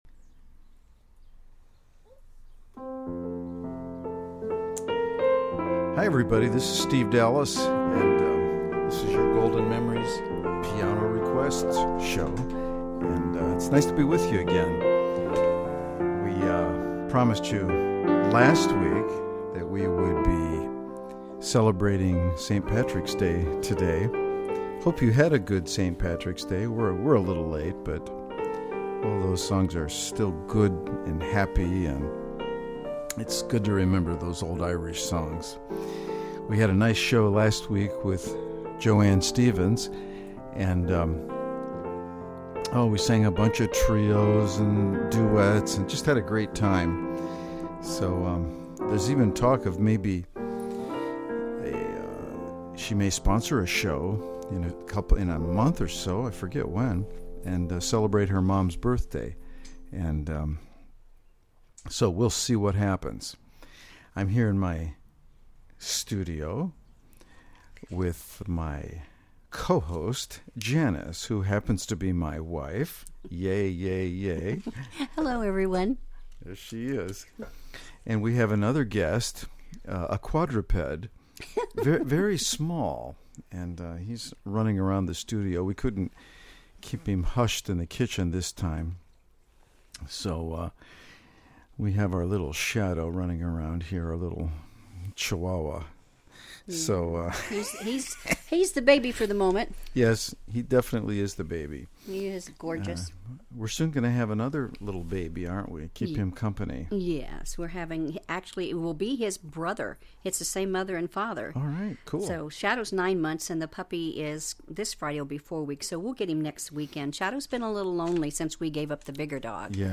Golden oldies played to perfection!